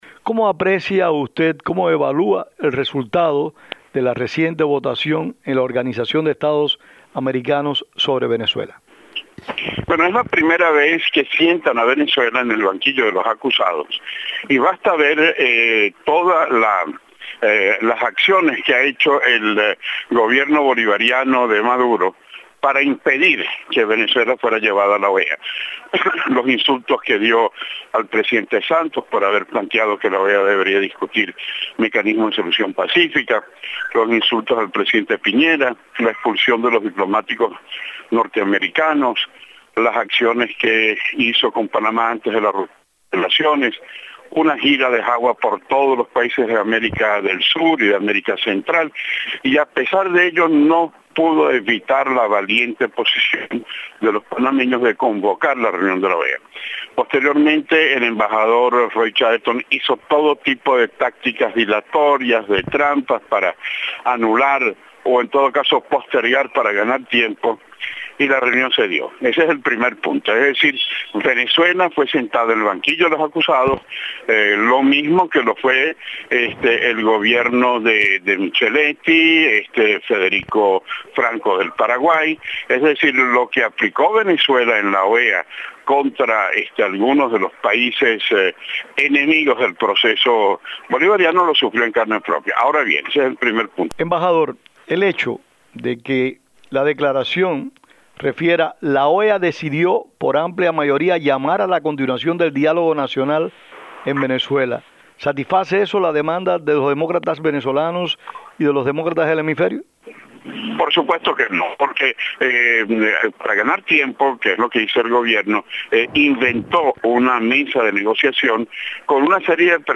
Milos Alcalay, entrevistado